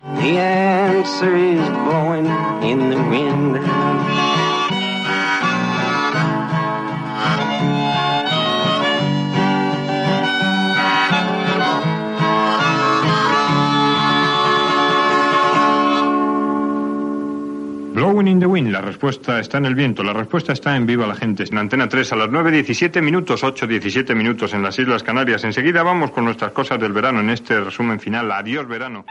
Tema musical, presentació del tema escoltat, hora i pas al resum de l'estiu
Entreteniment
FM